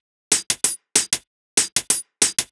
Index of /musicradar/ultimate-hihat-samples/95bpm
UHH_ElectroHatB_95-04.wav